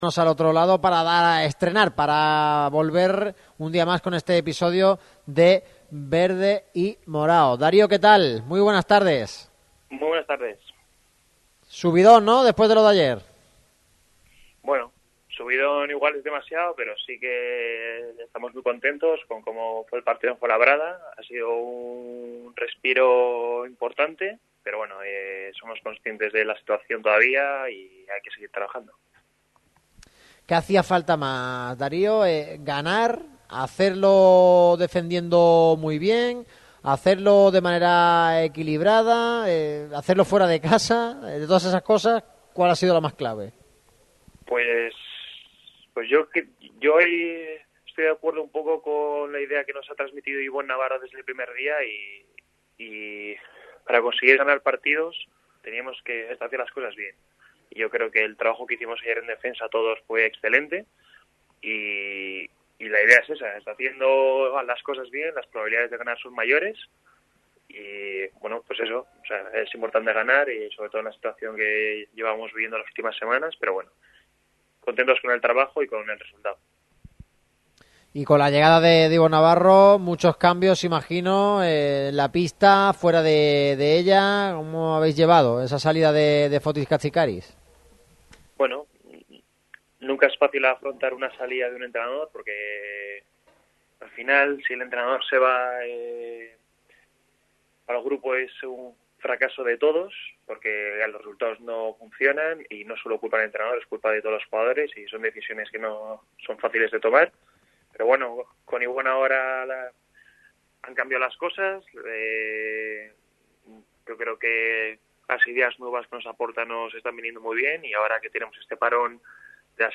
Nueva entrega de ‘Marca Basket, 40 años en verde y morado’, patrocinada por la Fundación Unicaja. Darío Brizuela, actual jugador cajista, es el invitado de hoy. El alero vasco repasa toda la actualidad del club de Los Guindos.